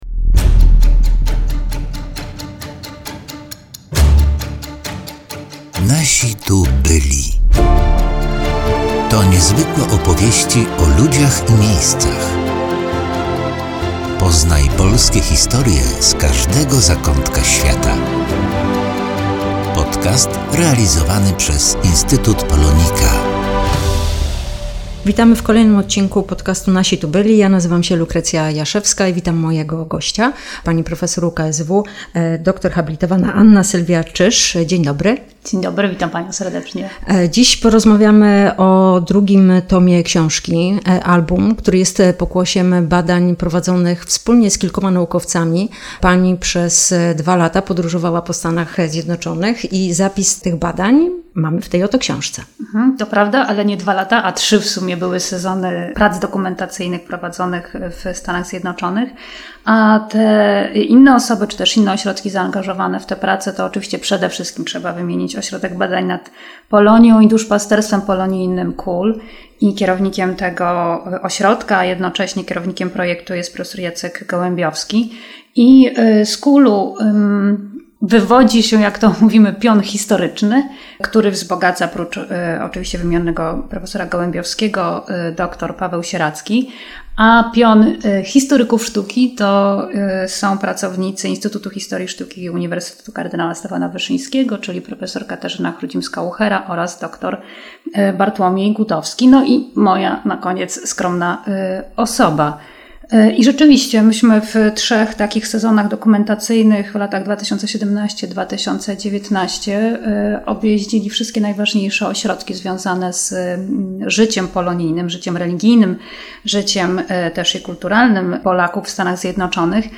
Rozmawiamy o książce Parafie i kościoły polskie w Michigan, Massachusetts i Minnesocie, która ukazała się nakładem wydawniczym Instytutu POLONIKA.